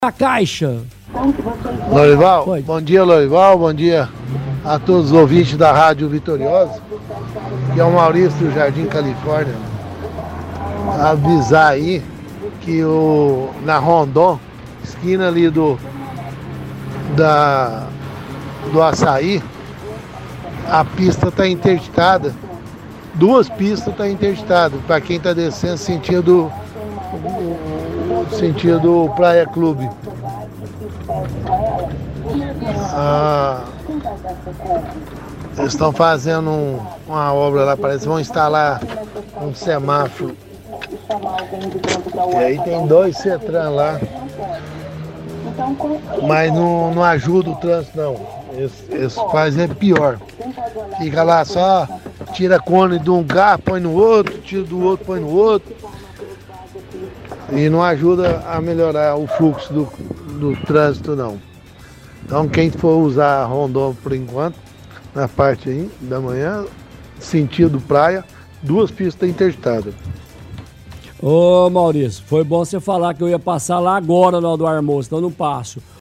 -Ouvinte alerta sobre interdição na av. Rondon Pacheco próximo ao Assaí. Diz que Settran não ajuda a melhorar o fluxo do trânsito.